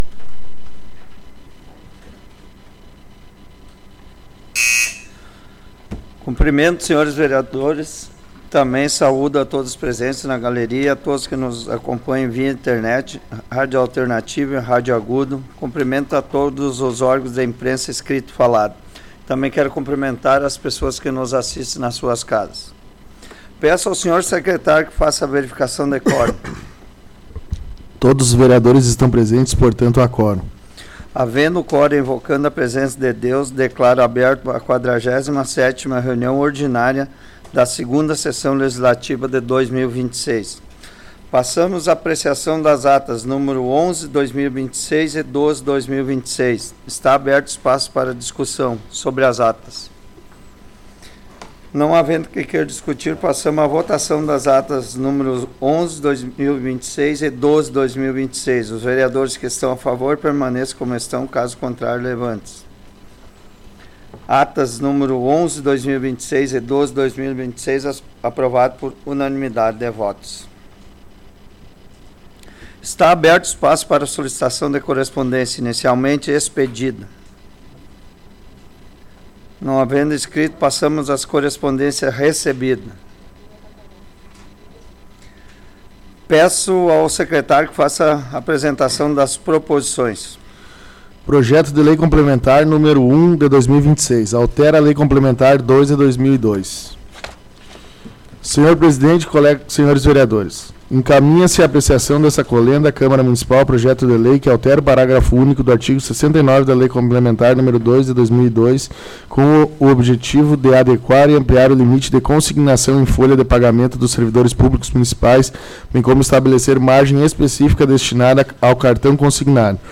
Áudio da 47ª Sessão Plenária Ordinária da 17ª Legislatura, de 16 de março de 2026